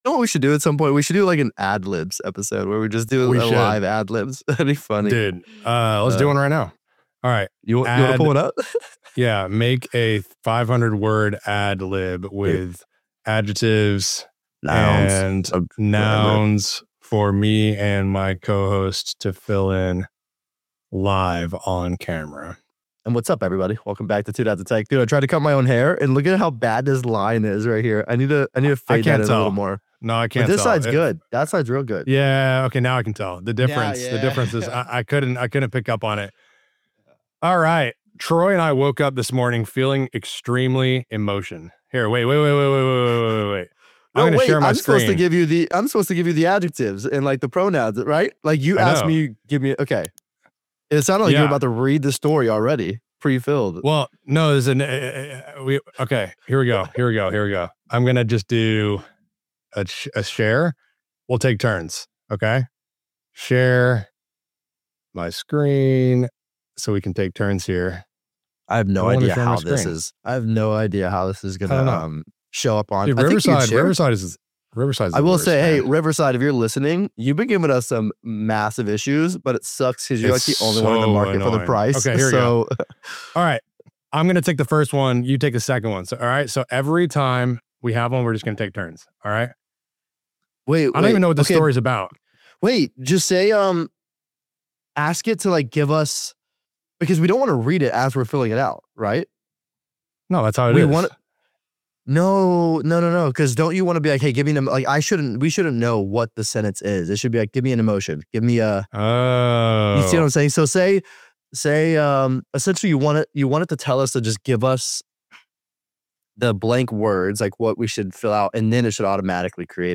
What starts as chaotic humor, Mad Libs, AI-generated chaos, and dad jokes, quickly turns into a serious conversation about technology addiction, the metaverse, robots in our homes, and how much of life we’re quietly giving away to comfort.